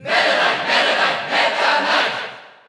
Category: Crowd cheers (SSBU) You cannot overwrite this file.
Meta_Knight_Cheer_Dutch_SSBU.ogg